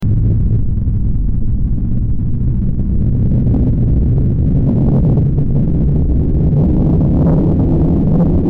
rakett8,5s.wav